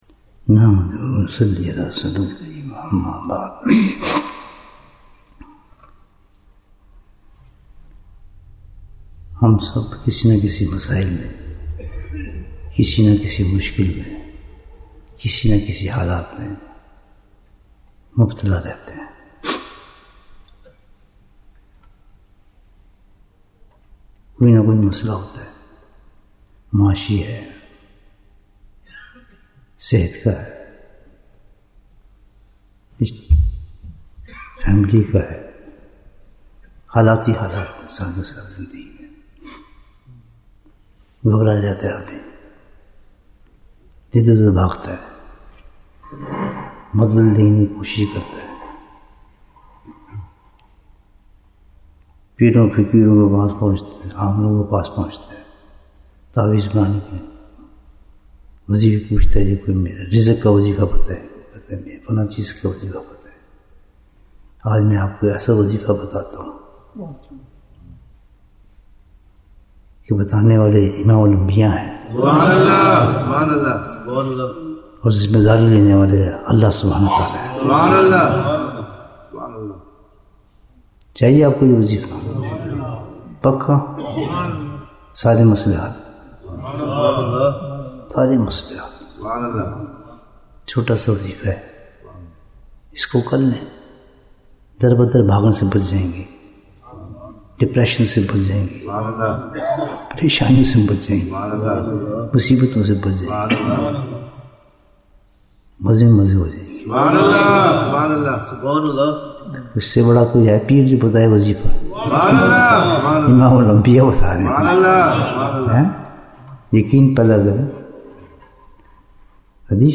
Bayan Episode 40- The Last Asharah in Manchester